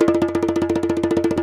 ASHIKO RO0PL.wav